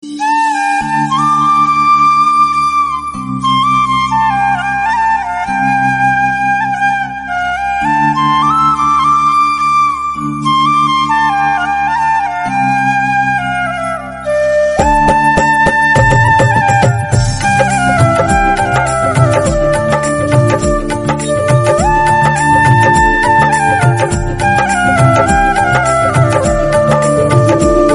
Categories: Flute Ringtones